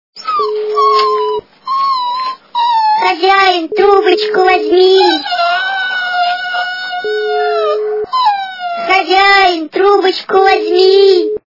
» Звуки » Люди фразы » Голос - Хозяин трубочку возьми
При прослушивании Голос - Хозяин трубочку возьми качество понижено и присутствуют гудки.